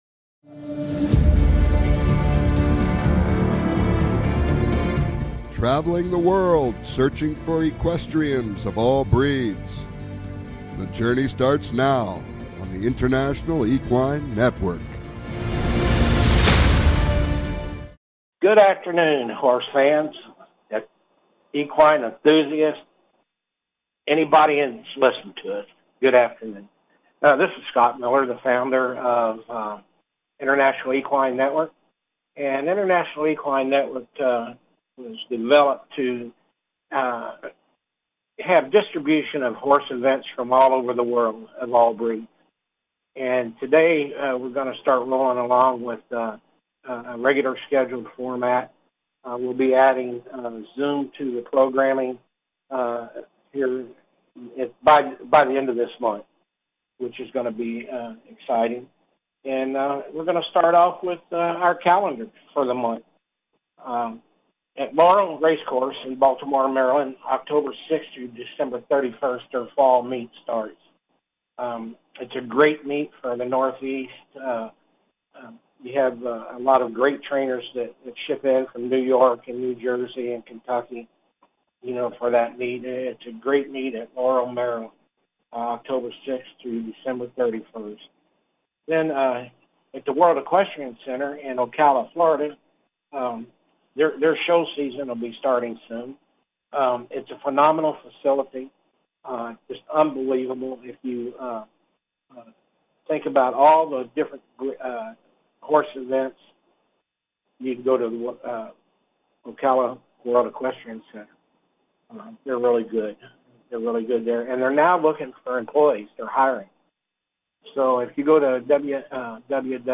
Subscribe Talk Show
This show will bring you the inside scoop! Calls-ins are encouraged!